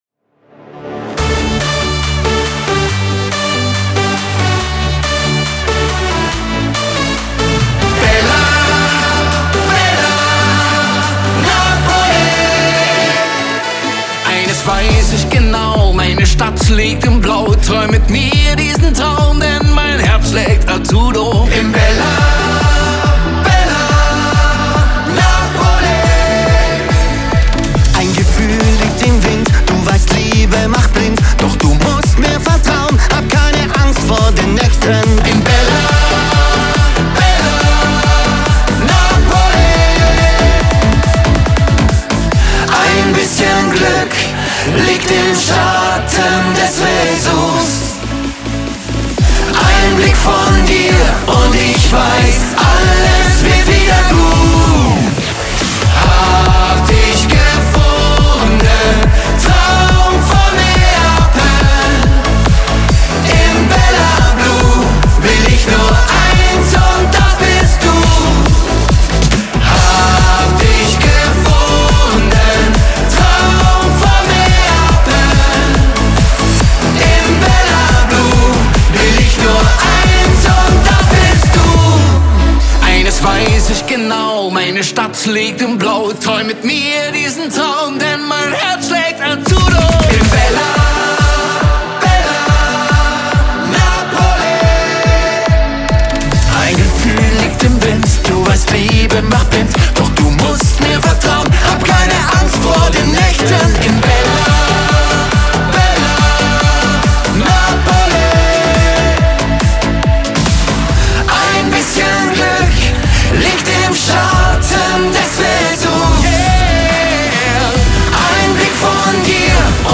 Genre: Schlager